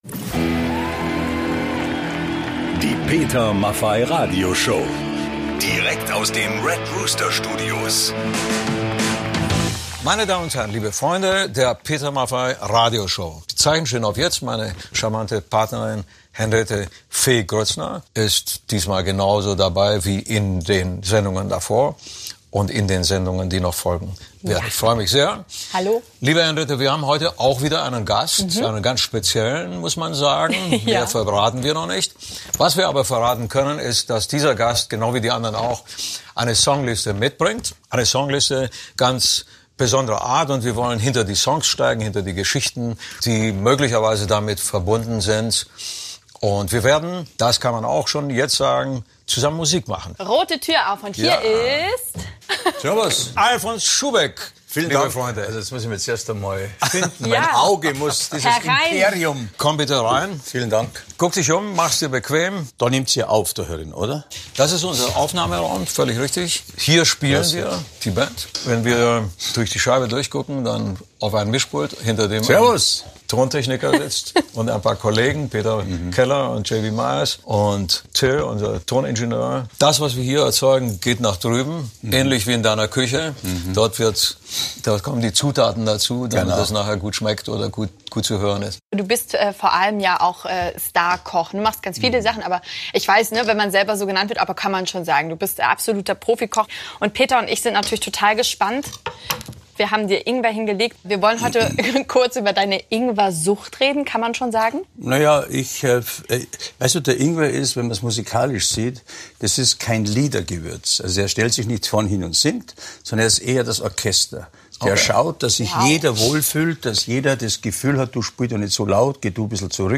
Natürlich performen Peter Maffay und Alfons Schuhbeck auch gemeinsam.
Für die Peter Maffay Radio Show holt er nun nach 50 Jahren die Gitarre wieder hervor!